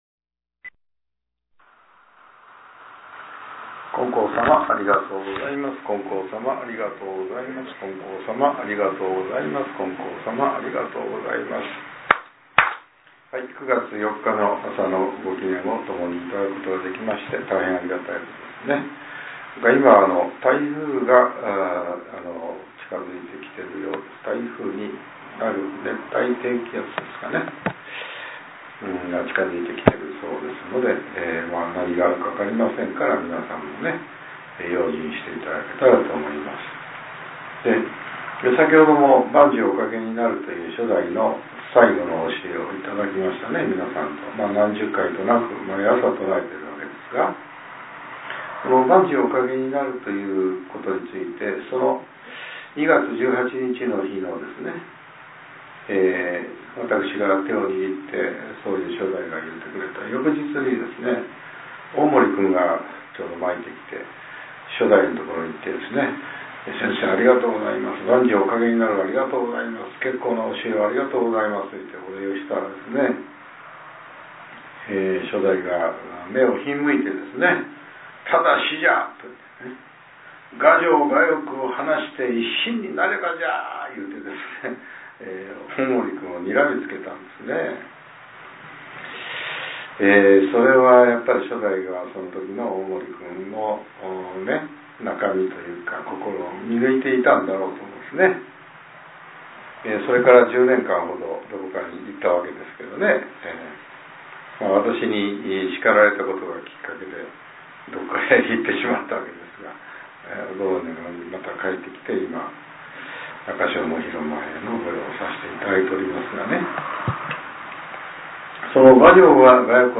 令和７年９月４日（朝）のお話が、音声ブログとして更新させれています。